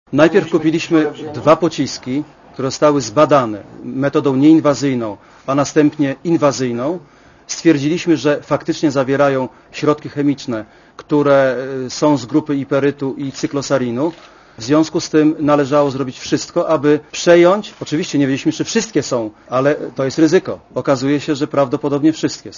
* Mówi generał Dukaczewski*